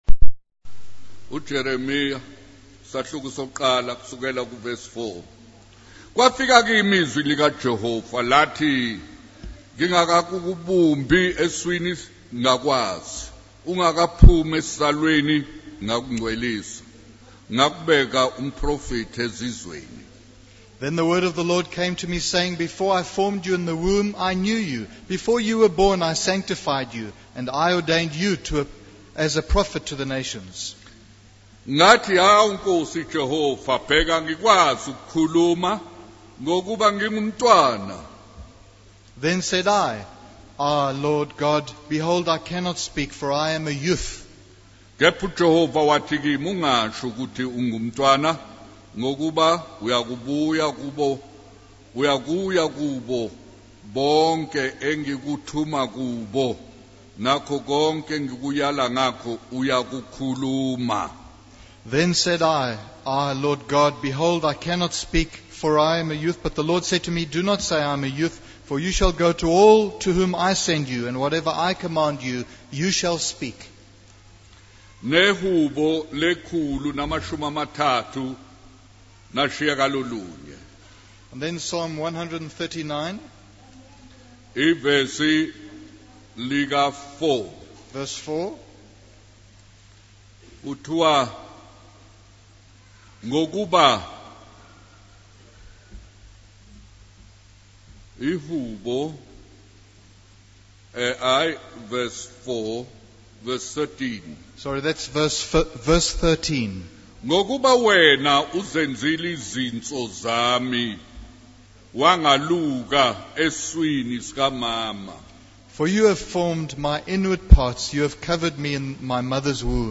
In this sermon, the speaker emphasizes the importance of winning souls for Christ. They mention statistics that show if every Christian were to win just one person to the Lord each year, the Gospel would conquer the whole world in 33 years. The speaker urges the audience to actively share the gospel and not be spiritually barren.